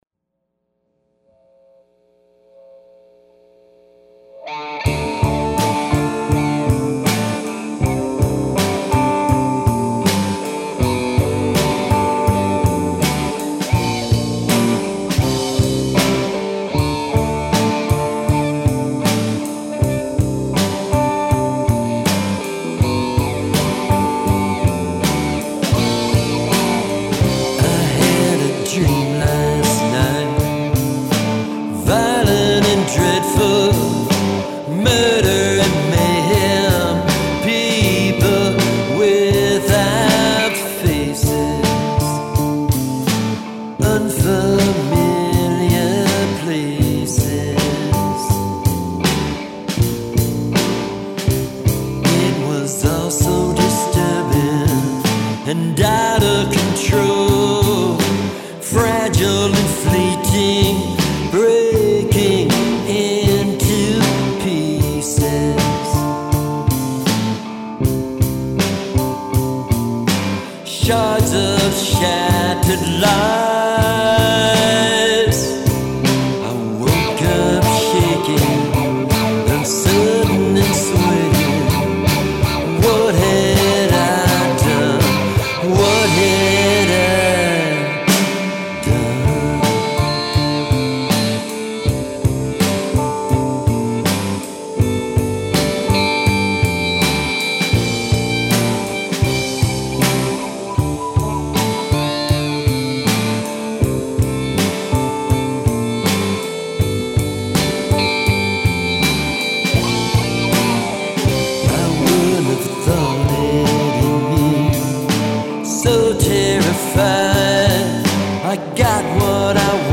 Theremin Stays! were recorded in Chicago at Handwritten